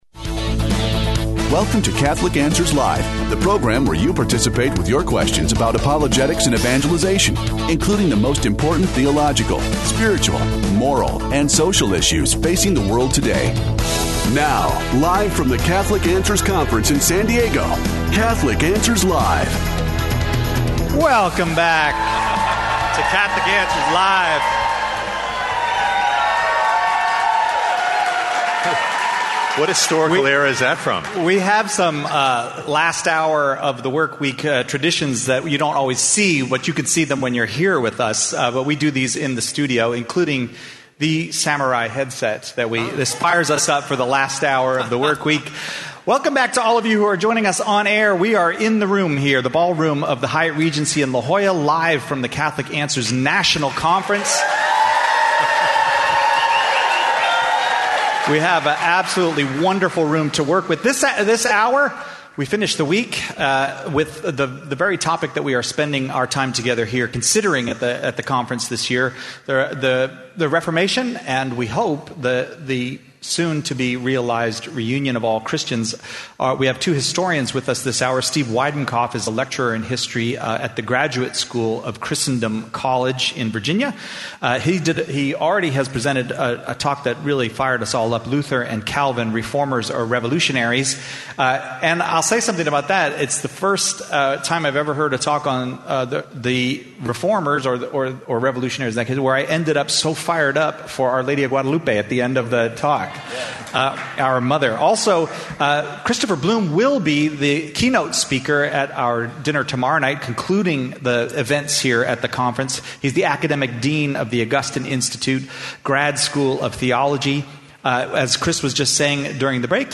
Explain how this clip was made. Live from the Catholic Answers National Conference, we are joined by two historians to ask what really happened in the Reformation Questions Covered: 02:30 &#8...